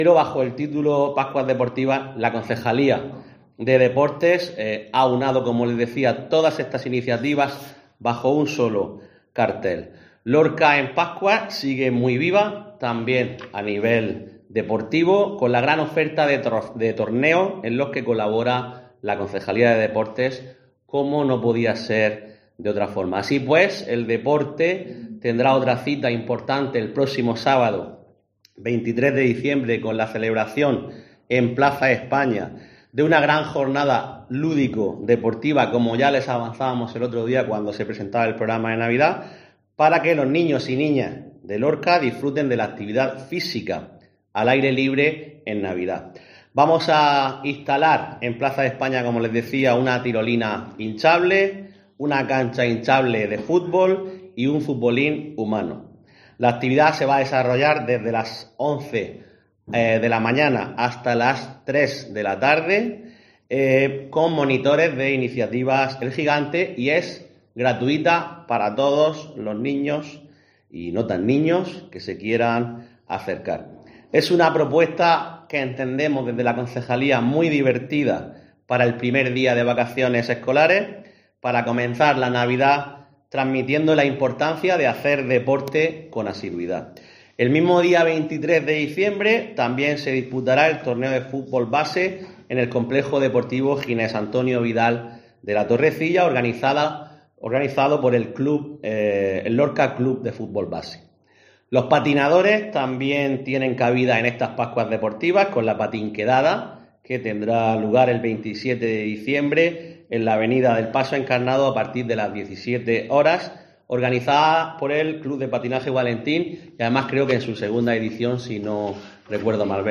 Juan Miguel Bayonas, concejal deportes del Ayuntamiento de Lorca